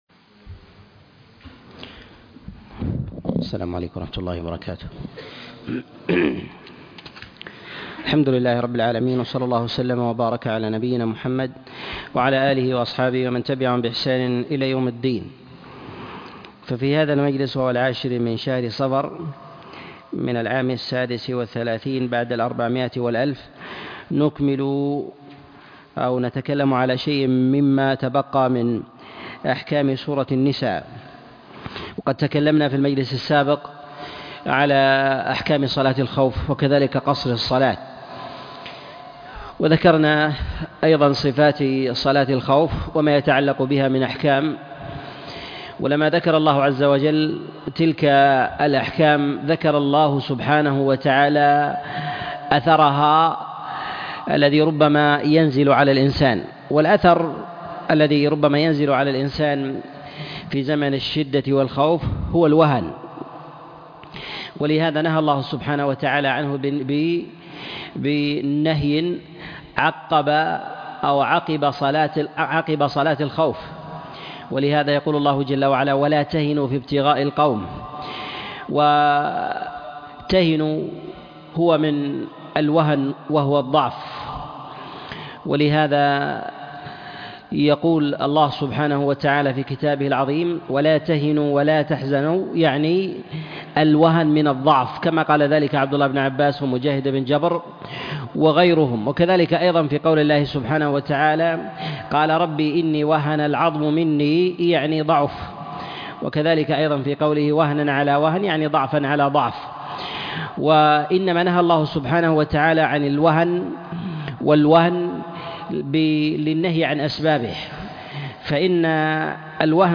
تفسير سورة النساء 25 - تفسير آيات الأحكام - الدرس 79